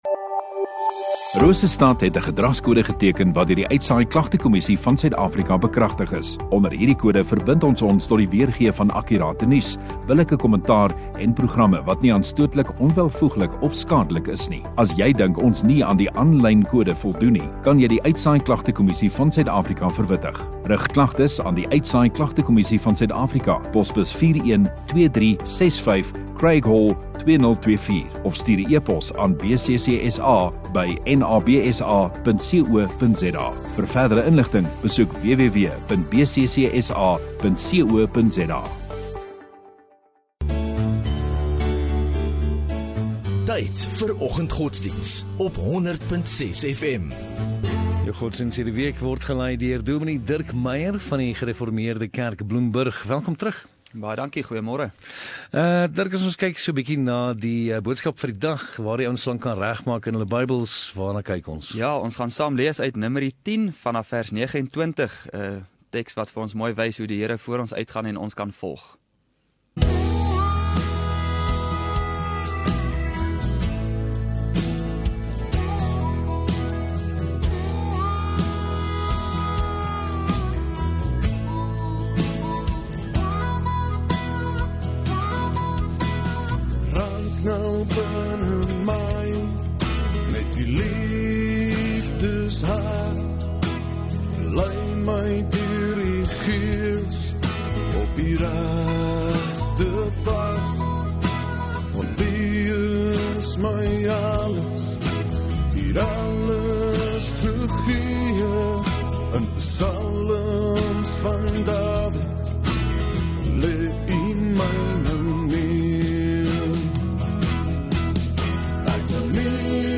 30 Apr Dinsdag Oggenddiens